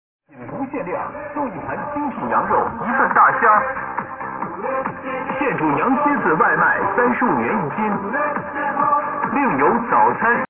标题: 扰民：一个大喇叭，一直响，影响恶劣
一个大喇叭，一直响，影响恶劣，有没有人管？？？打环境局电话说不管